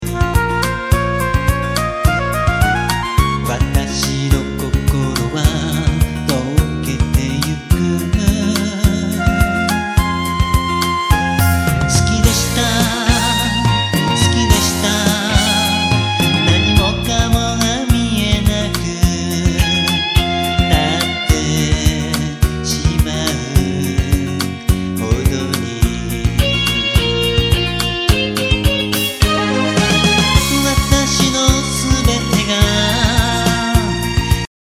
ちょっぴり　 　 ＭＰ３ファィル・・・637ＫＢ　急に音が出ます！音量注意！！